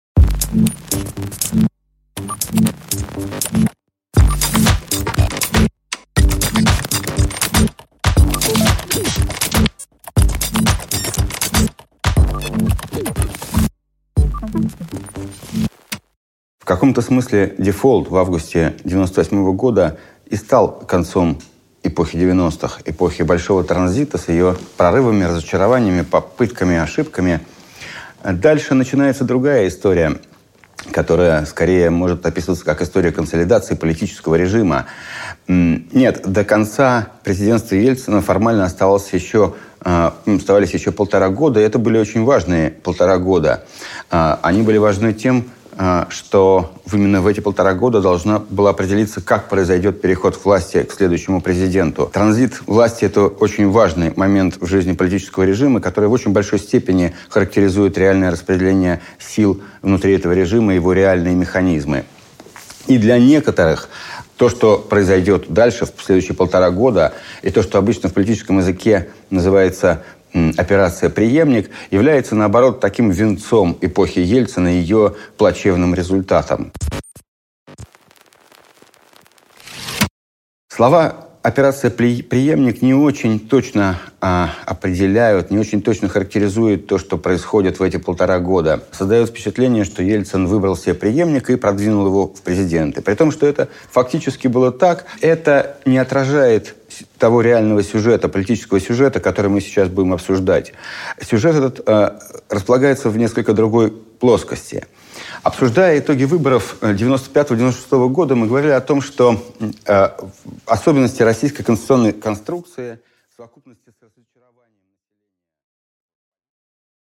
Аудиокнига Последние выборы | Библиотека аудиокниг